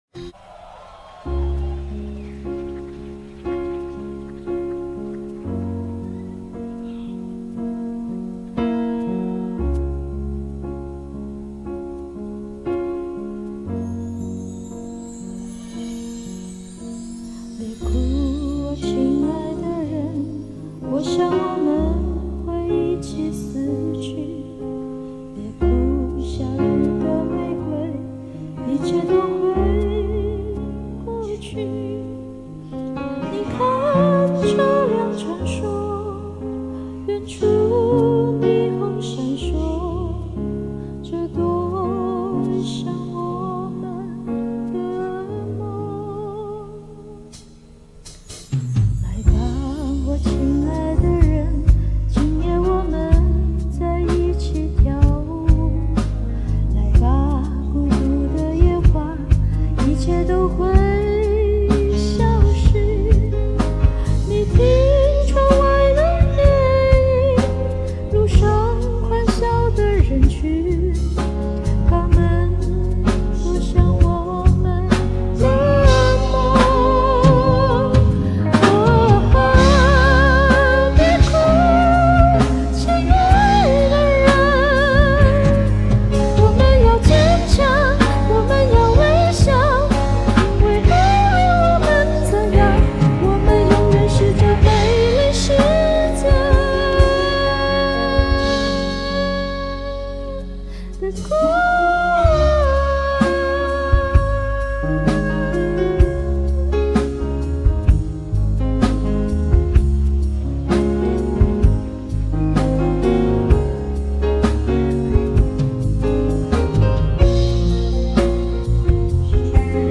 降2key，需要一支好点的麦，需要去掉音量均衡。
我很喜欢这首歌，不过女声不太容易唱出原来的感觉，最后还是调高音域才能有那种极限感。